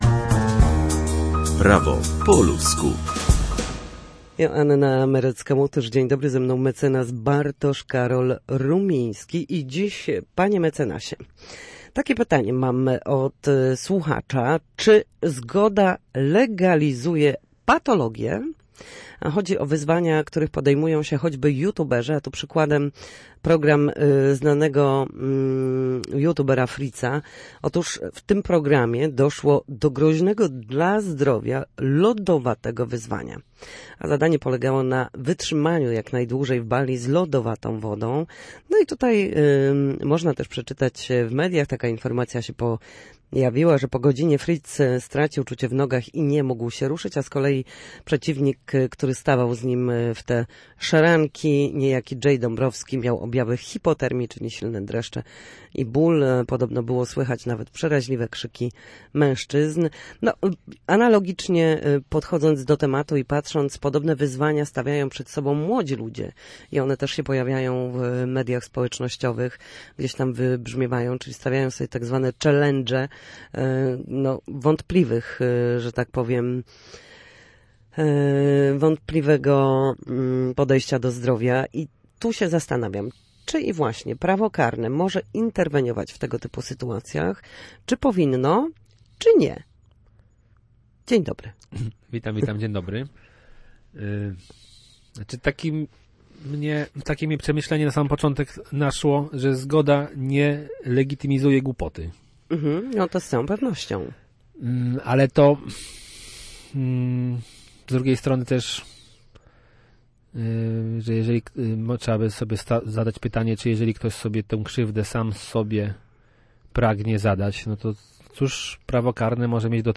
W każdy wtorek o godzinie 13:40 na antenie Studia Słupsk przybliżamy państwu meandry prawa. Nasi goście – prawnicy – odpowiadać będą na jedno pytanie dotyczące zachowania w sądzie czy podstawowych zagadnień prawniczych.